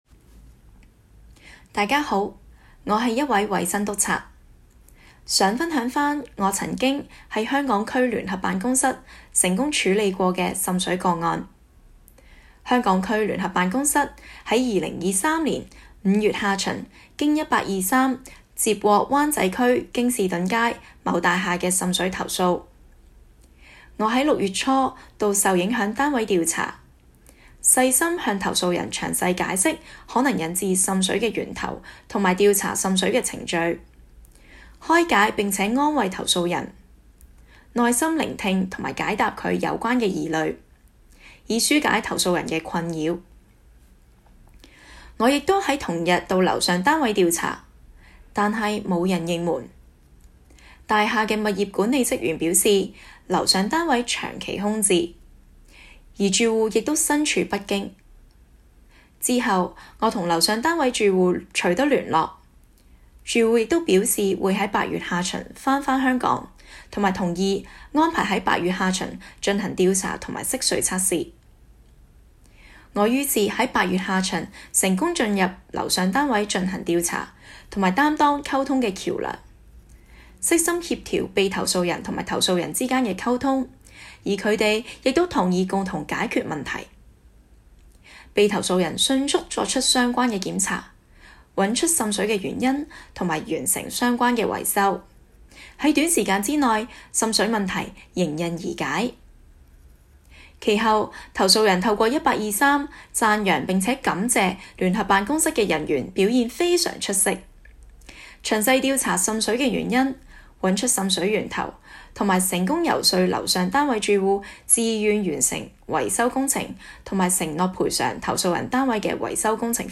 Case No. Year (Region) The voice of Joint Office investigating officer